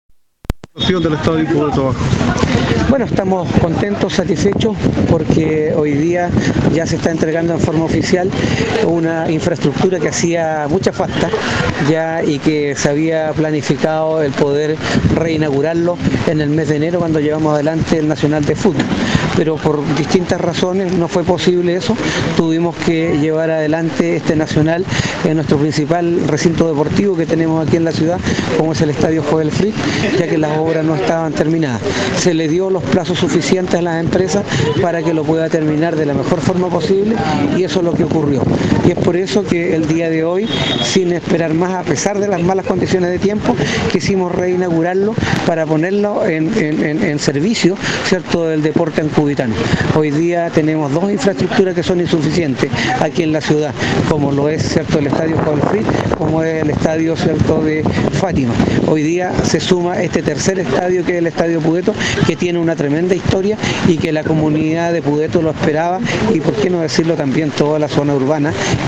Bajo una lluvia permanente se desarrolló la ceremonia de inauguración del proyecto de mejoramiento del Estadio Pudeto en Ancud.
Finalmente el Alcalde de Ancud, Carlos Gómez, se mostró contento y agradecido con este remodelado recinto deportivo.